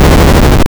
wall_move.wav